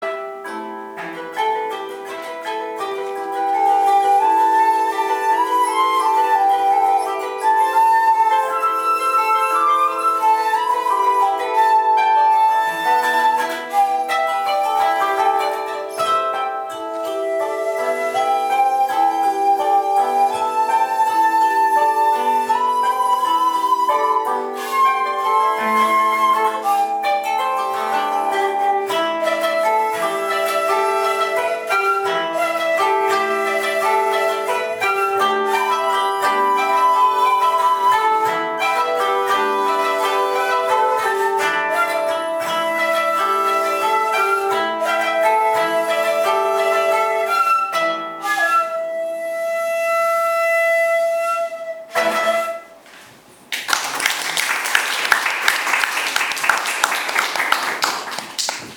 番号（１１）と同じメロディーの繰り返しですので、より三拍子のリズムにのって吹きましょう。